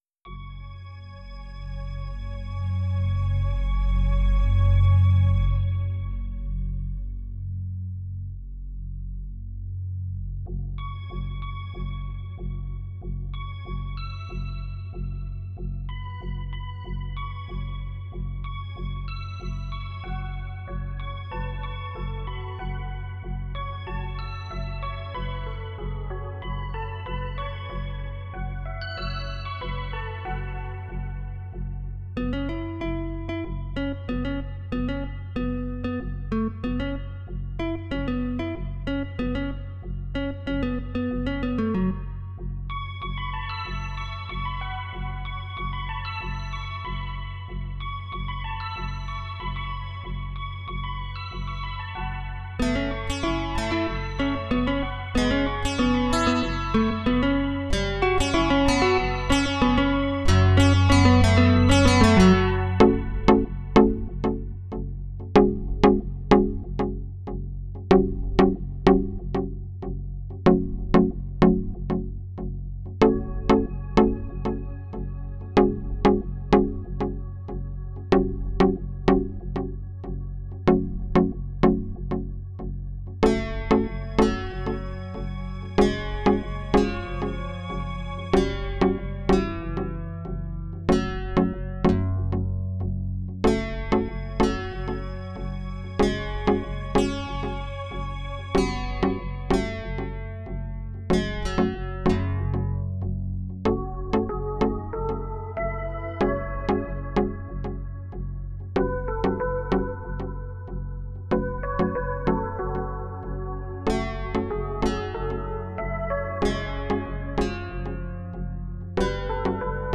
Title Tom And Becky Opus # 520 Year 2020 Duration 00:03:01 Self-Rating 3 Description From _Tom Sawyer_, Tom and Becky are lost in a cave. mp3 download wav download Files: mp3 wav Tags: Guitar, Digital Plays: 111 Likes: 14